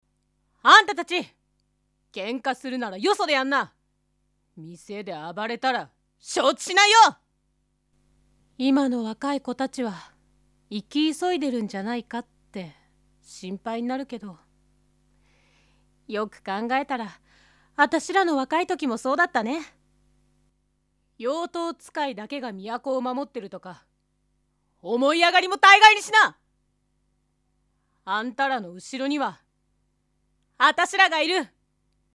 演じていただきました！
性別：女性